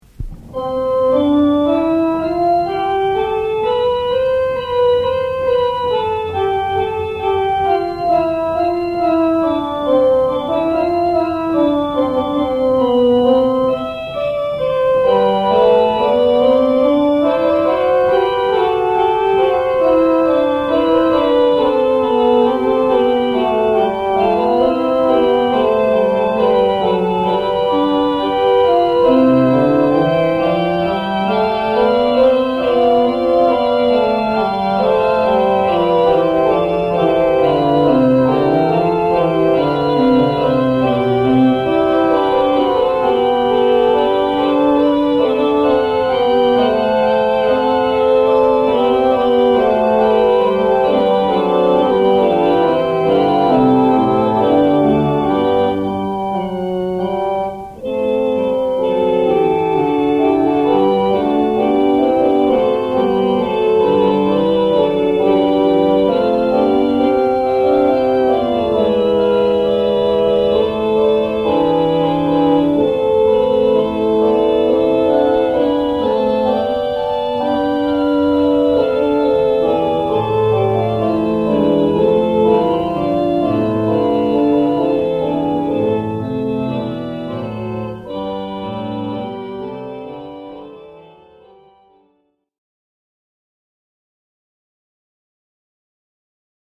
Orgel og píanólög
Páll Ísólfsson leikur í hljóðdæminu
Fuga yfir C.mp3